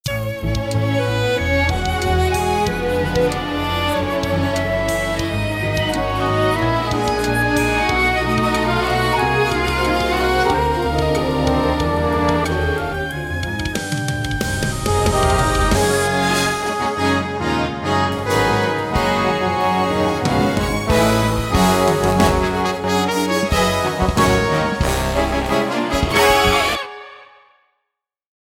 Orchestral92 BPMTriumphant